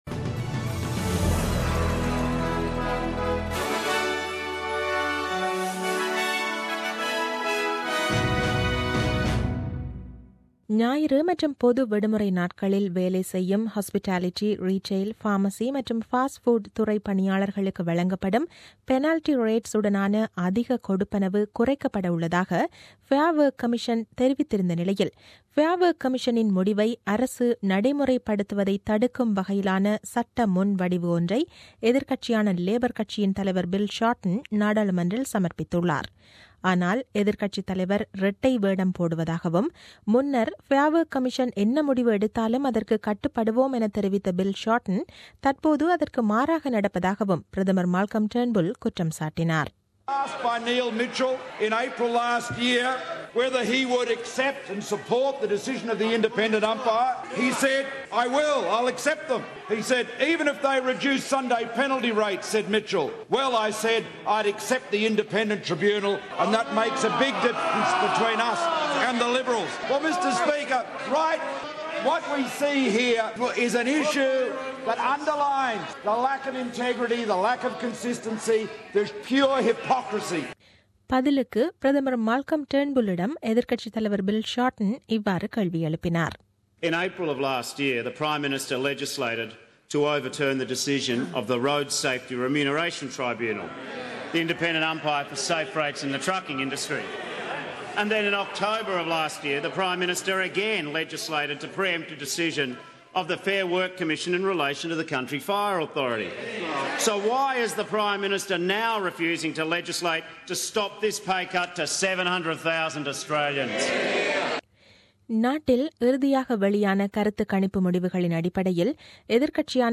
The news bulletin aired on 27 February 2017 at 8pm.